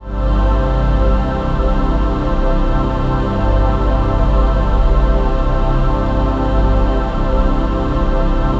DM PAD4-16.wav